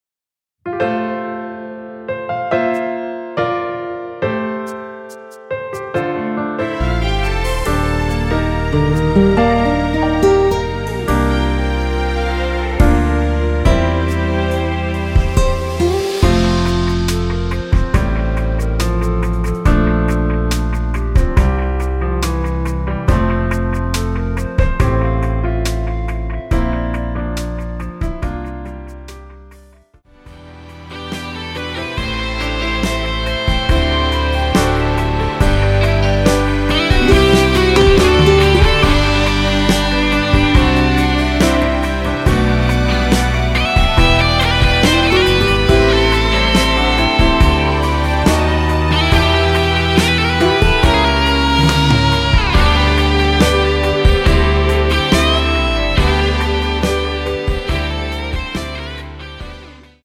원키에서(-3)내린 멜로디 포함된 MR입니다.
앞부분30초, 뒷부분30초씩 편집해서 올려 드리고 있습니다.
중간에 음이 끈어지고 다시 나오는 이유는
곡명 옆 (-1)은 반음 내림, (+1)은 반음 올림 입니다.
(멜로디 MR)은 가이드 멜로디가 포함된 MR 입니다.